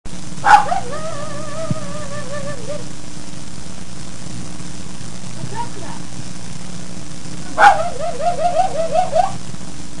柴犬の雑種
知らない人がきたとき
テープからのマイク録音のため、
ちょっと音質が悪いのですが､
わんわんワンワン　10sec　69kB）